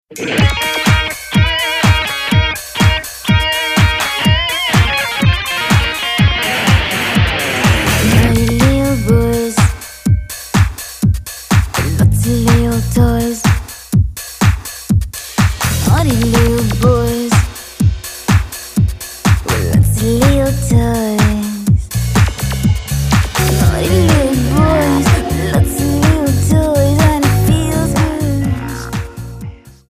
Alternative,Dance,Rock